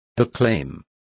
Complete with pronunciation of the translation of acclaiming.